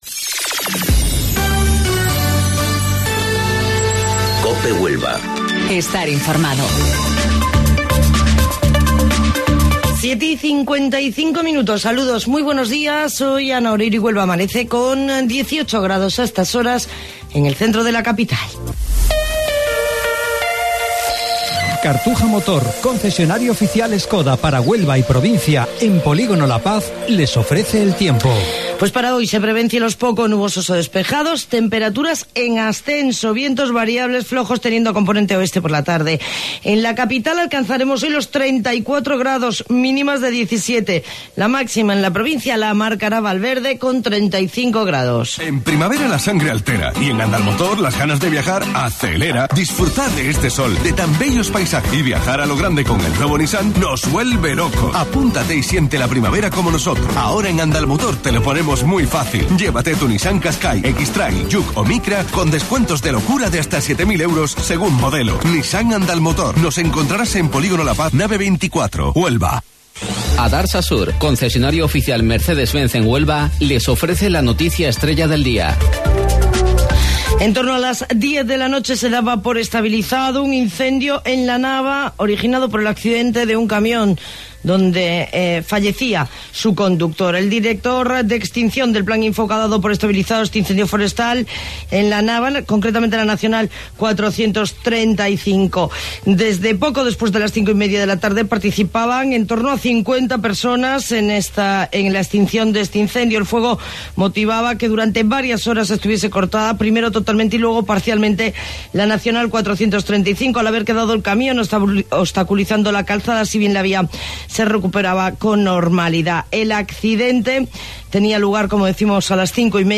AUDIO: Informativo Local 07:55 del 28 de Mayo